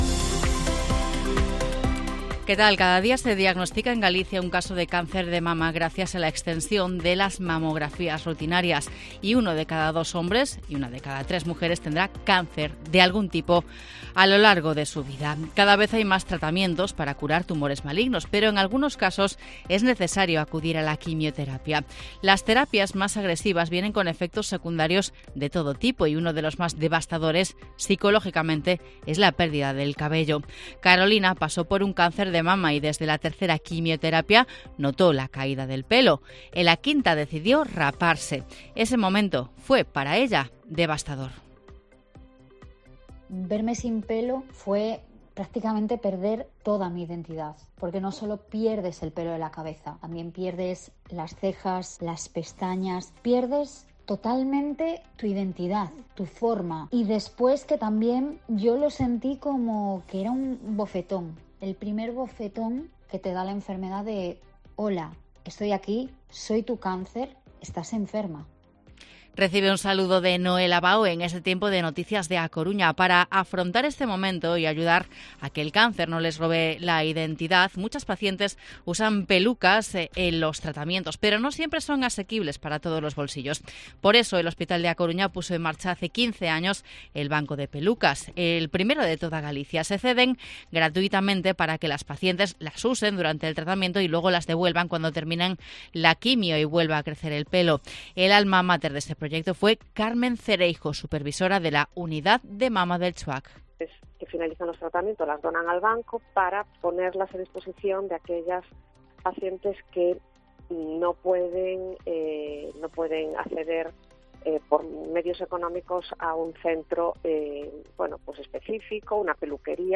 Informativo Mediodía COPE Coruña miércoles, 27 de septiembre de 2023 14:20-14:30